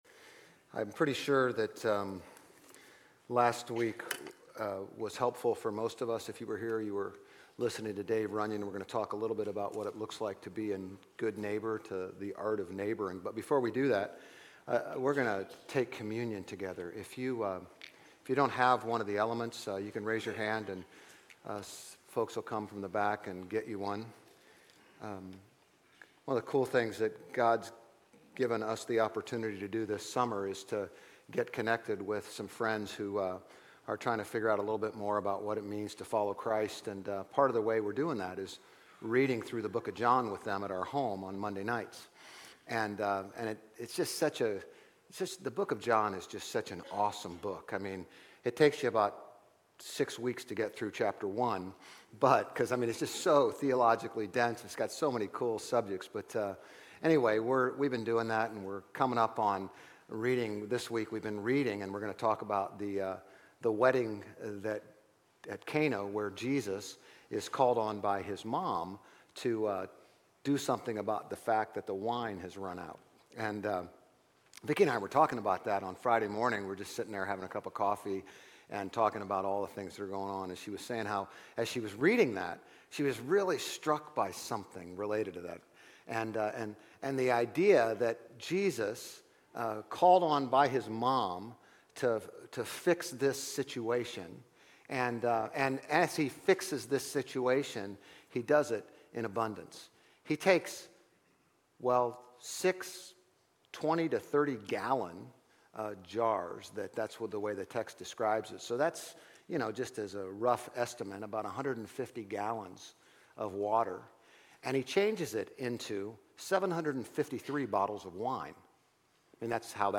GCC-OJ-September-3-Sermon.mp3